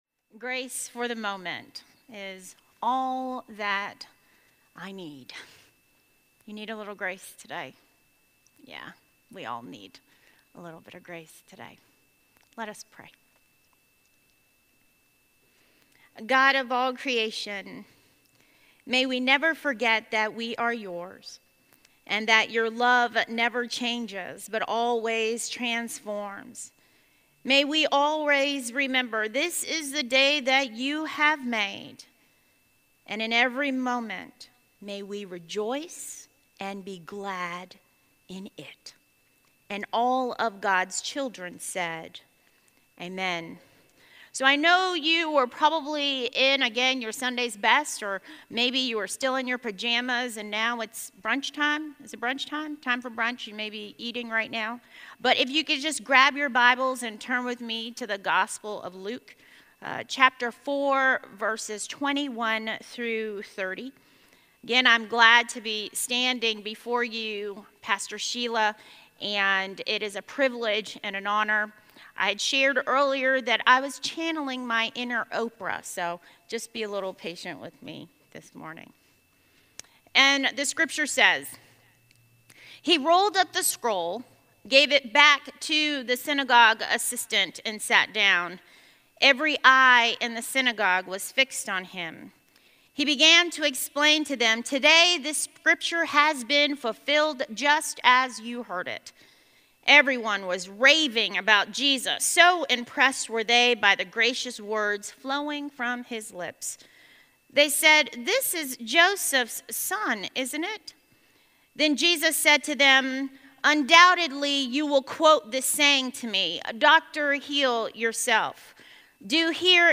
A message from the series "Lent: Christ Is for Us."